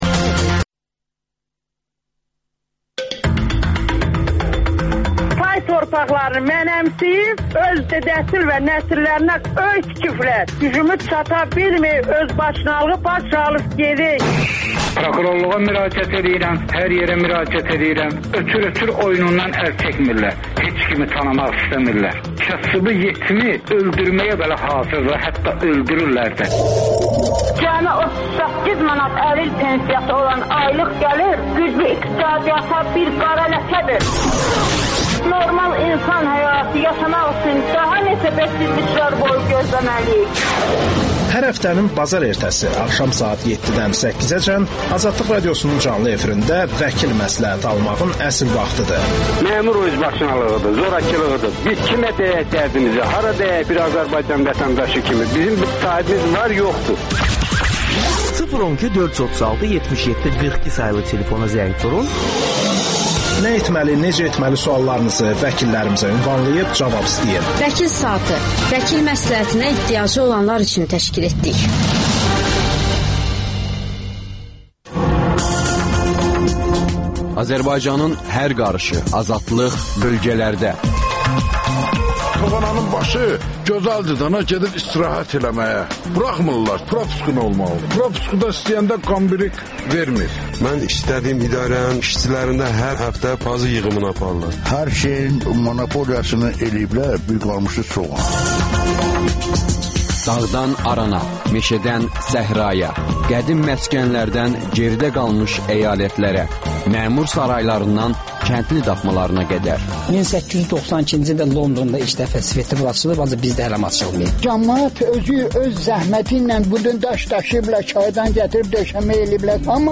AzadlıqRadiosunun müxbirləri ölkə və dünyadakı bu və başqa olaylardan canlı efirdə söz açırlar.